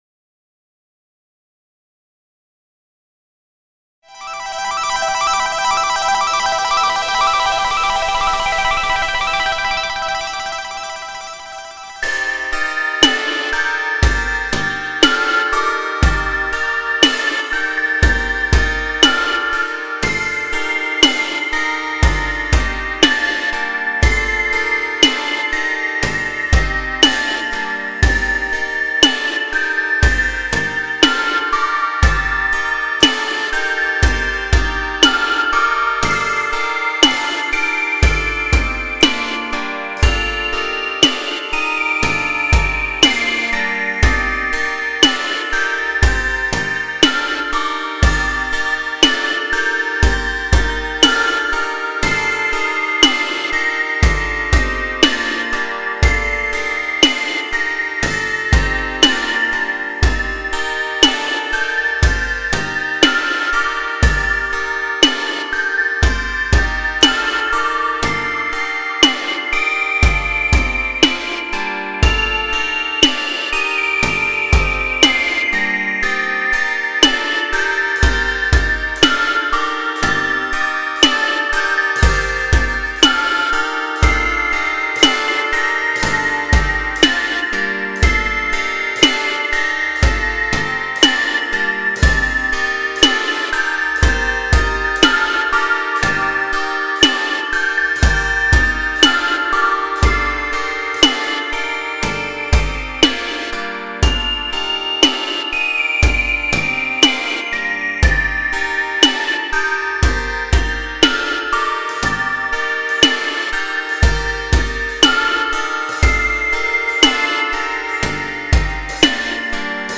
EDMロング民族